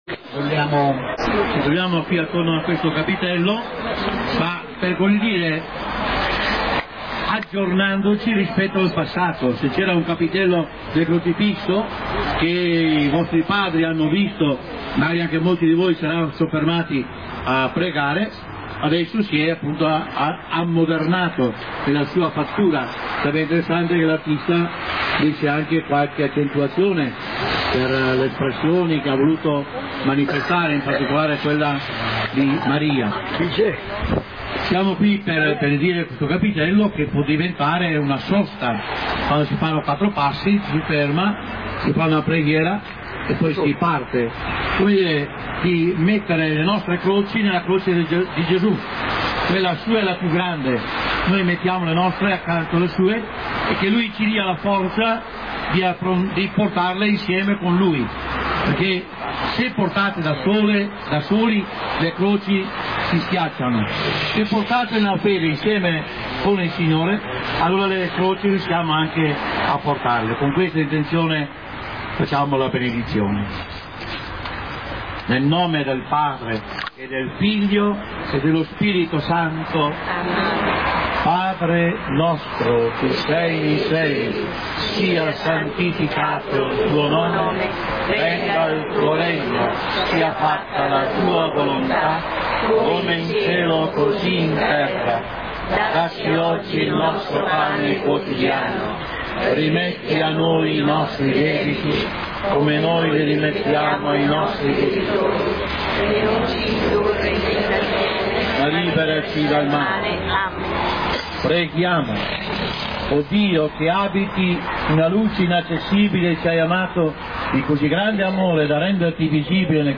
Alla cerimonia inaugurale, sono intervenuti:
benedizione-Vescovo-Zenti.mp3